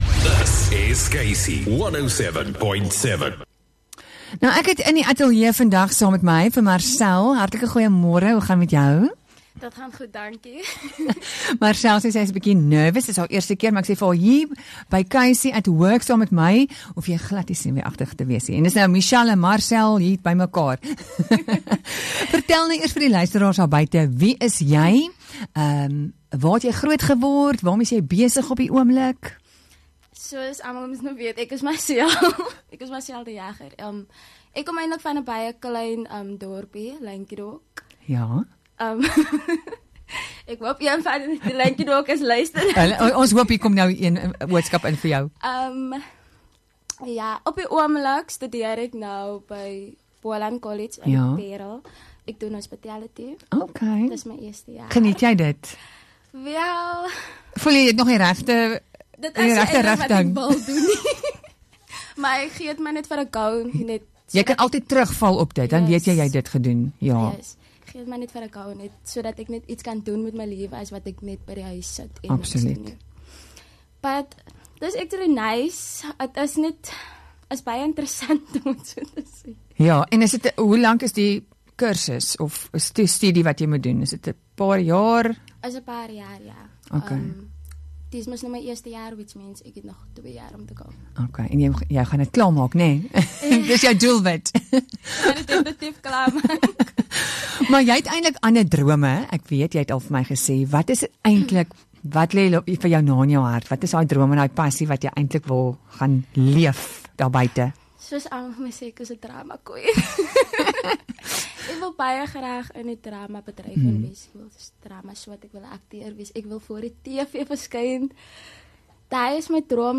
Interview with student sharing her dreams and passions